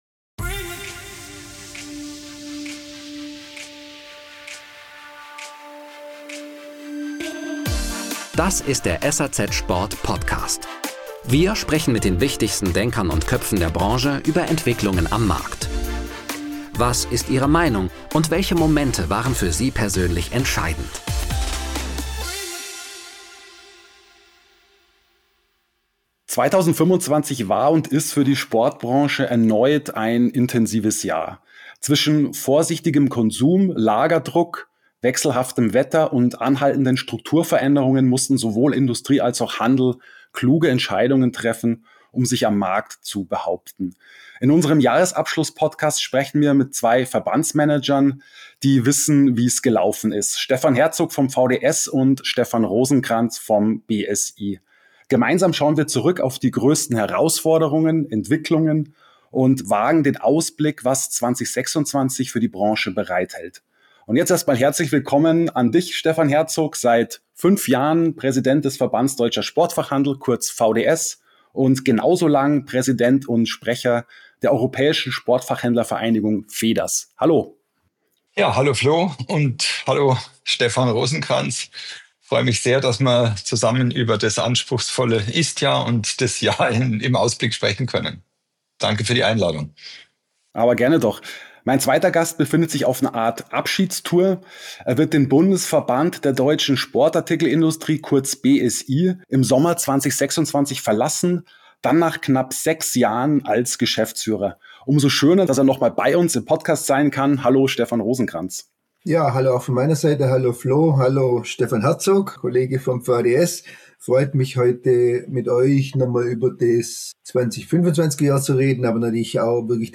Ein Gespräch über Insolvenzen, politische Signale – und über die Chancen, die der Branche 2026 neuen Schwung verleihen könnten.